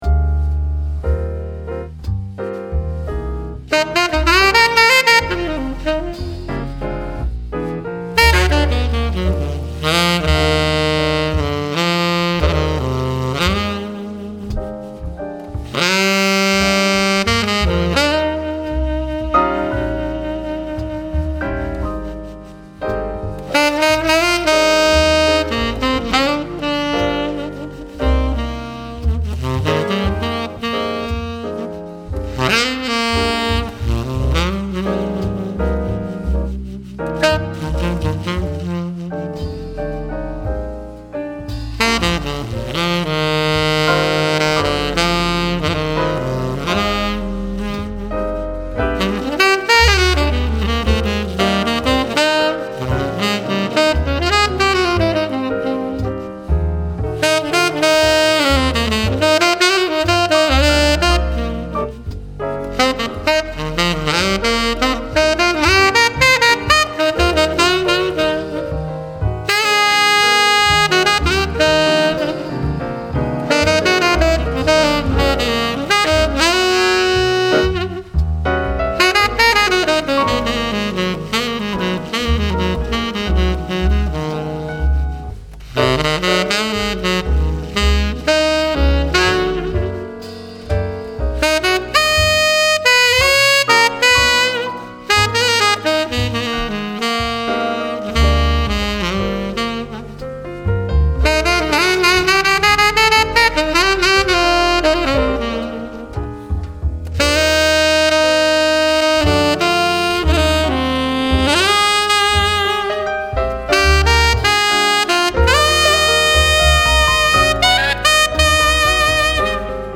素材： 真鍮（ヴィンテージブラス） 24金メッキ チェンバー形状 ：丸形 バッフル： ミディアムバッフル 音色と特徴： 明るすぎず暗すぎない音色、 スタイル： ジャズ、 推奨リード： 天然素材のリード